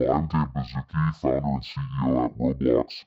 The voice of Blookie.
BlookieVoice.mp3